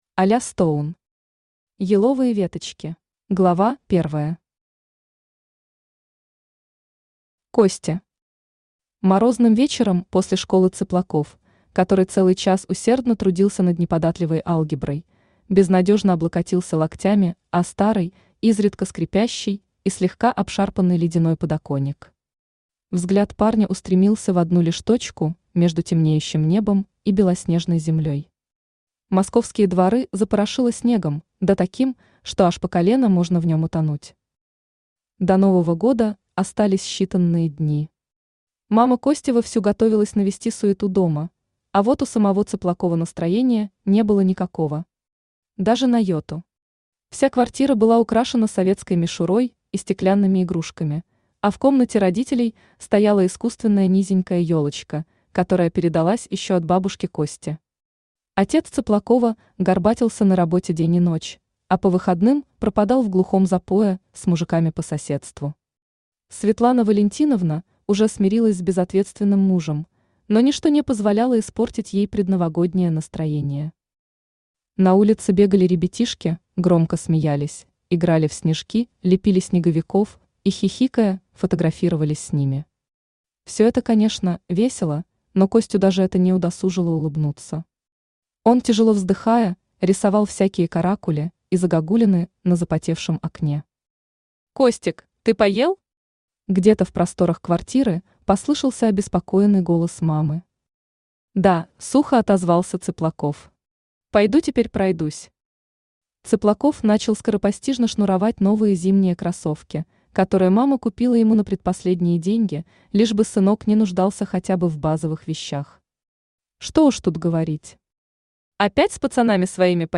Аудиокнига Еловые веточки | Библиотека аудиокниг
Aудиокнига Еловые веточки Автор Аля Стоун Читает аудиокнигу Авточтец ЛитРес.